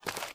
STEPS Dirt, Walk 13.wav